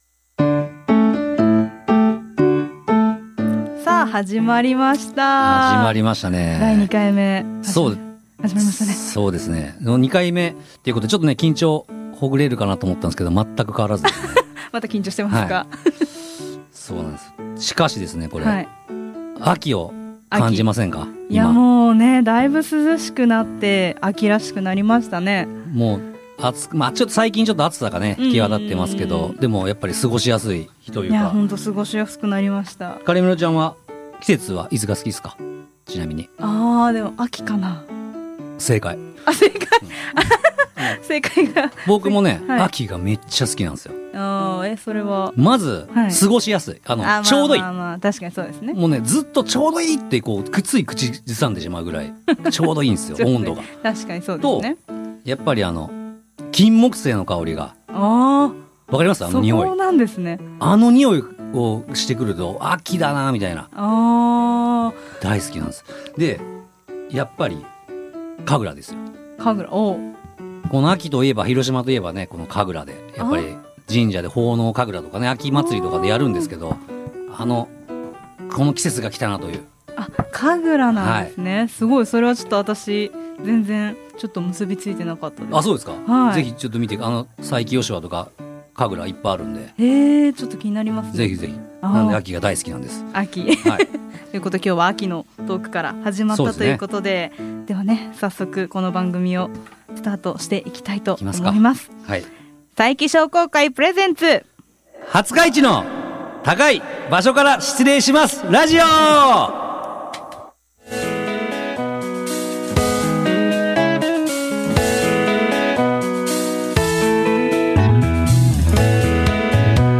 761.jp/new/wp-content/uploads/2025/10/ab9aee2099a920dcd9cd2bfdbc91f960.mp3 ※著作権保護のため、楽曲は省略させて頂いています。